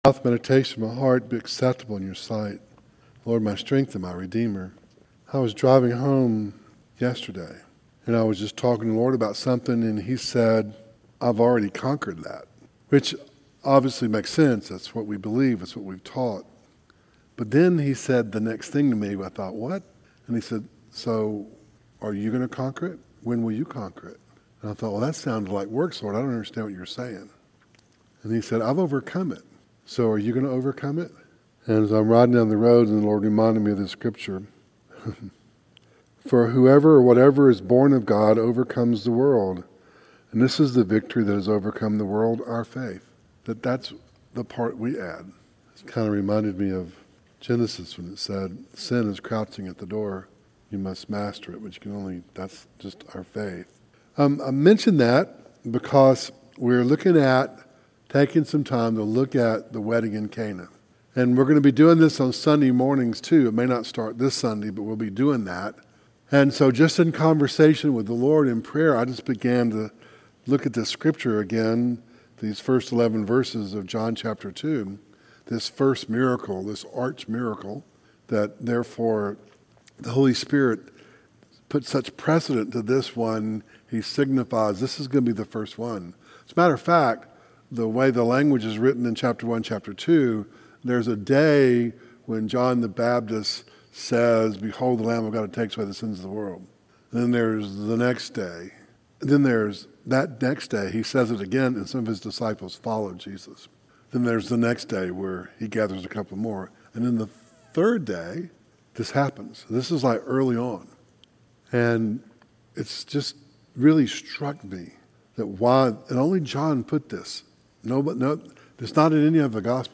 Service Type: Devotional